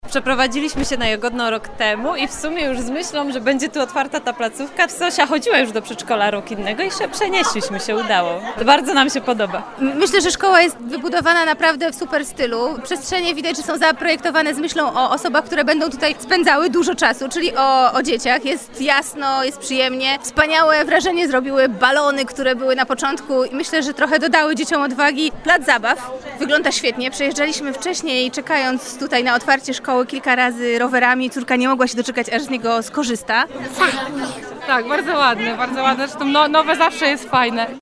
01_sonda-rodzice-o-nowym-przedszkolu-Asfaltowa.mp3